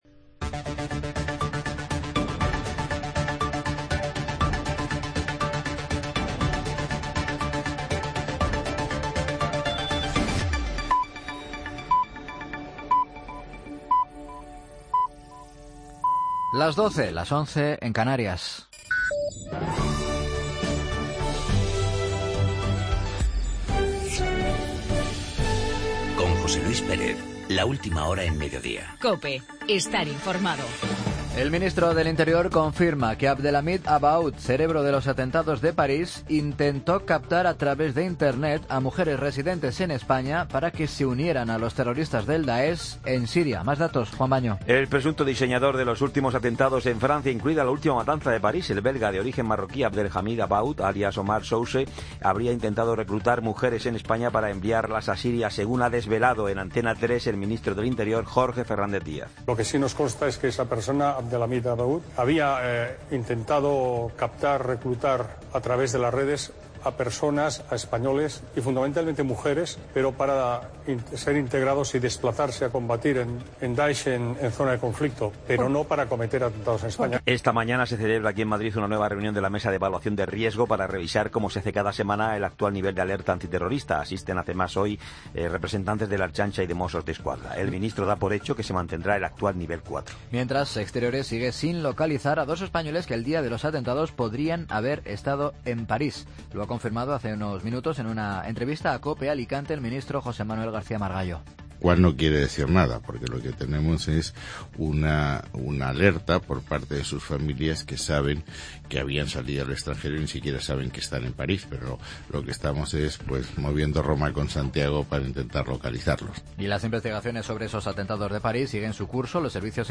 Noticias de las 12 h jueves 19 de noviembre de 2015